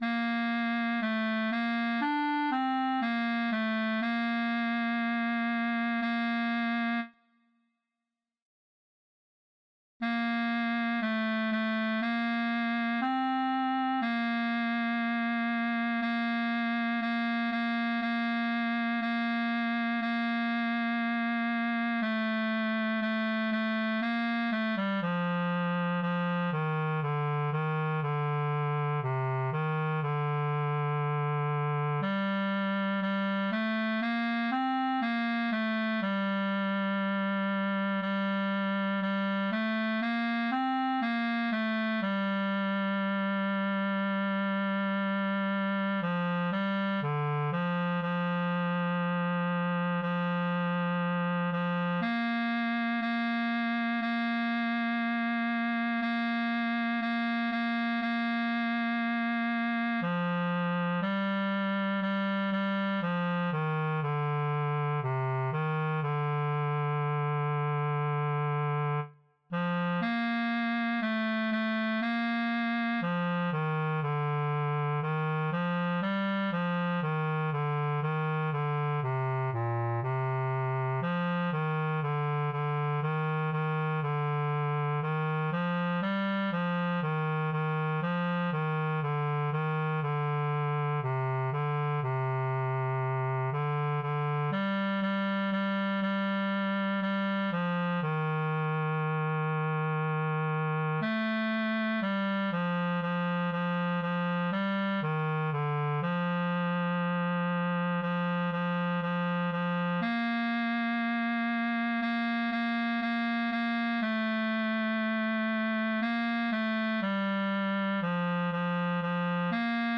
Partitura, cor mixt (pdf): Mare eşti, Doamne!